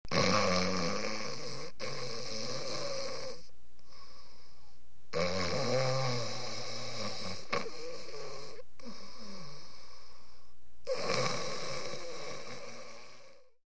Звуки детского храпа